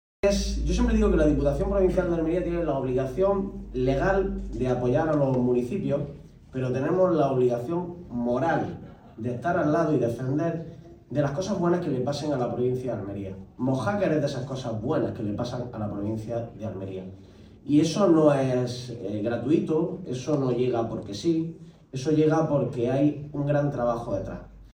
El acto se celebró en el Hotel Puntazo de Mojácar Playa y reunió a representantes institucionales, profesionales del sector turístico y miembros de la sociedad mojáquera.